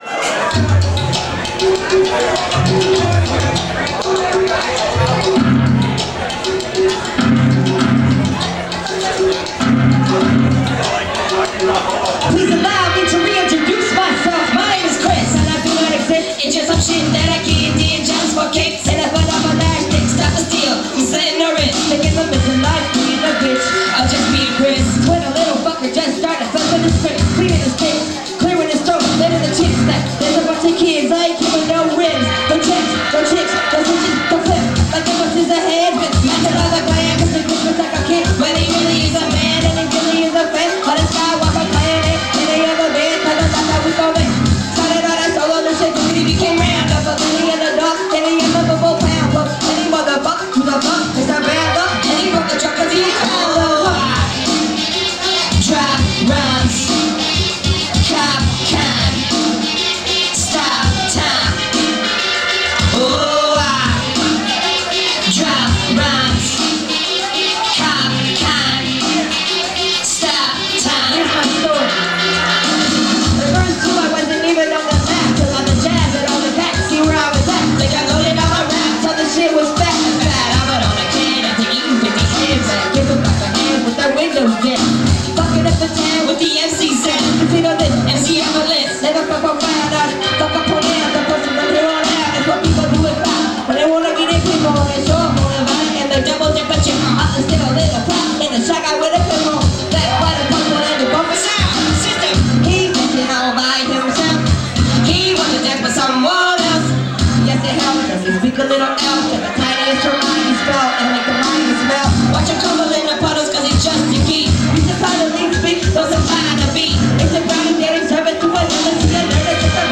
baton rouge, la at the spanish moon on february 4th 2005
audience dvcam (audio rip) recording